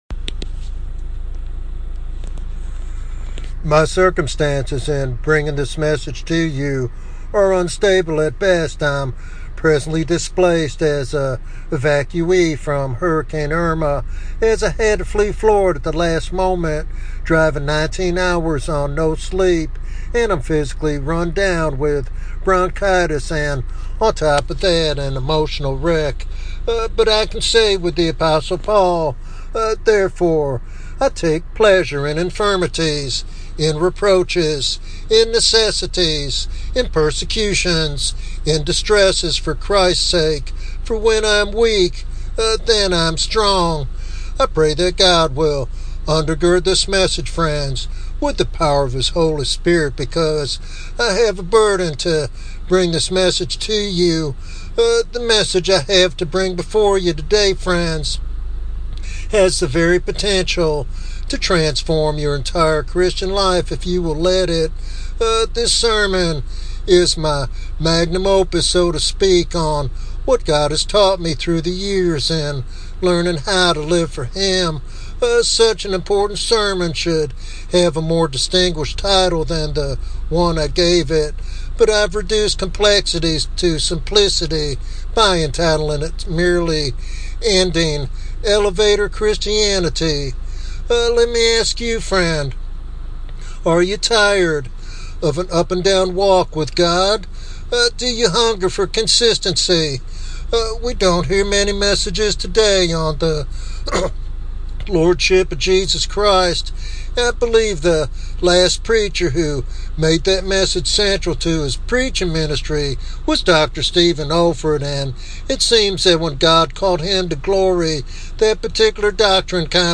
This sermon challenges believers to move beyond superficial faith and experience the liberty and power that come from living fully under Christ's reign.